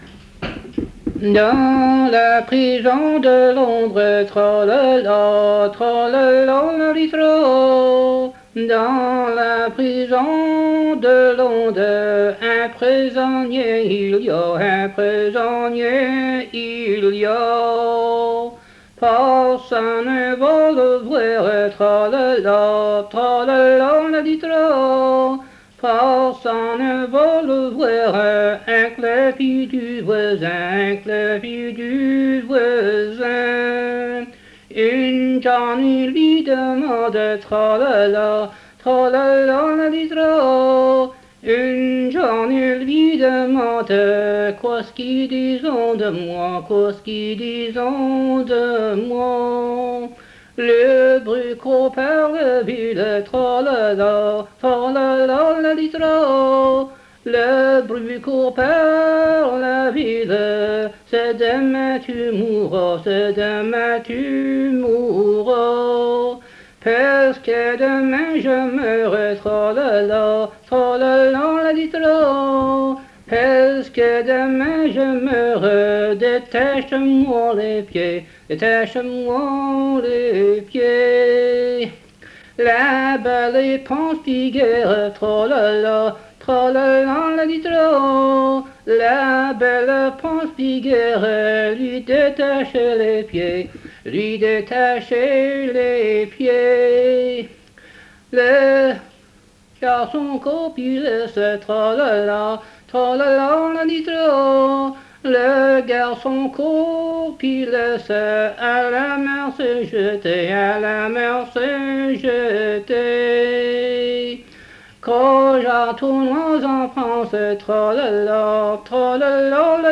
Chanson Item Type Metadata
Emplacement La Grand'Terre